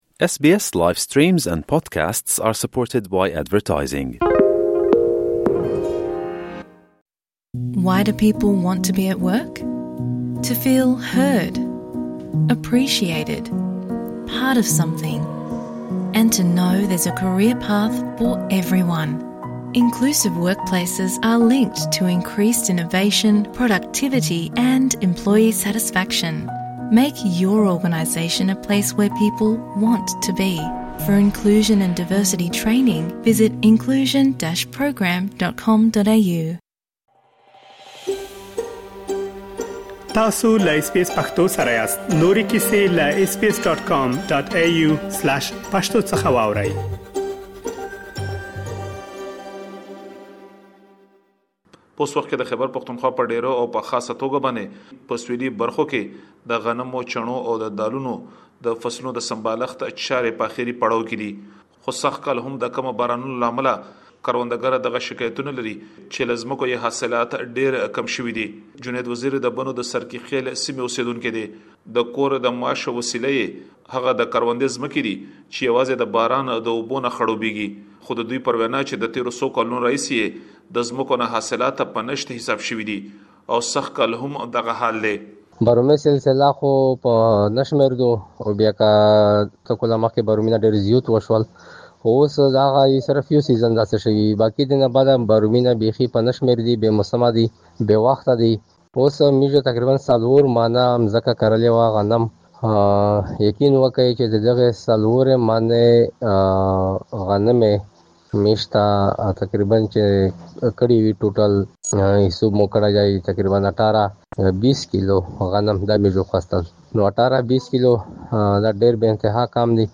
په خیبر پښتونخوا کې، د کم باران له امله د للمي ځمکو حاصلات له ۷۰ څخه تر ۸۰ سلنې پورې کم شوي دي. تاسو کولی شئ په دې اړه نور معلومات دلته په راپور کې واورئ.